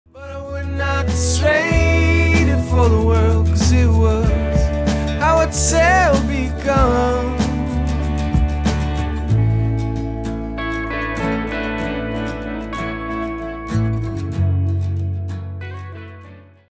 So the song got louder and hopefully clearer through the process, and what we are left with is a song that is at a commercial loudness level.